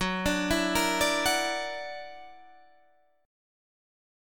Gb+7 chord